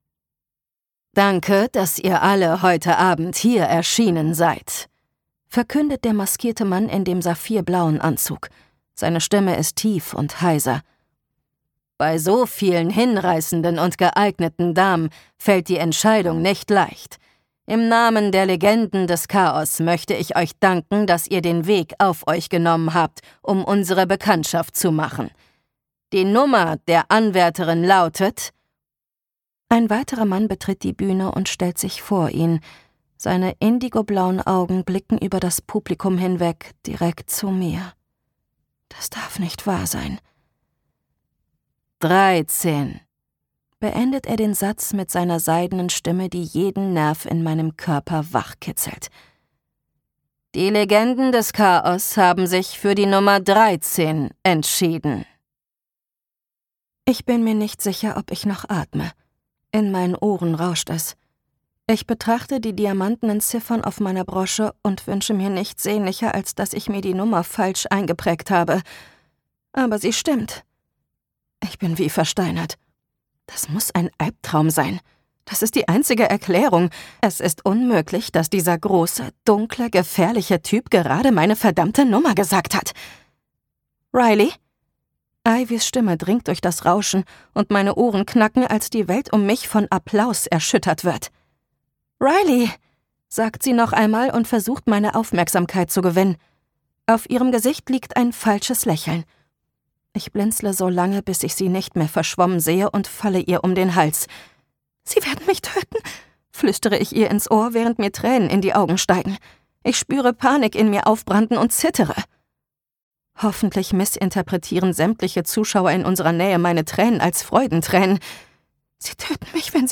The Never List - Jade Presley | argon hörbuch
Gekürzt Autorisierte, d.h. von Autor:innen und / oder Verlagen freigegebene, bearbeitete Fassung.